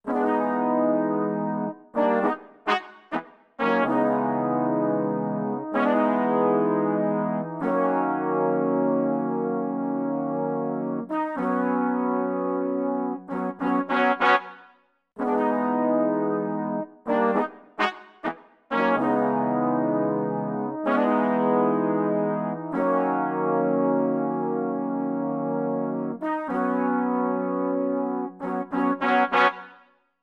14 brass 1 A2.wav